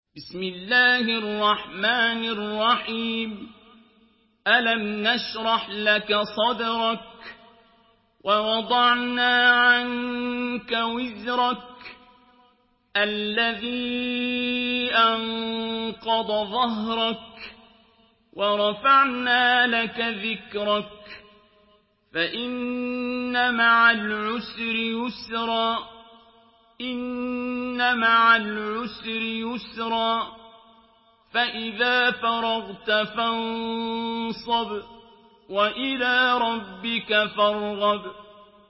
Surah আশ-শারহ MP3 by Abdul Basit Abd Alsamad in Hafs An Asim narration.
Murattal Hafs An Asim